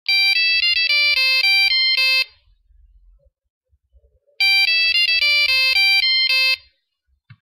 📞 Incoming Call from Asset sound effects free download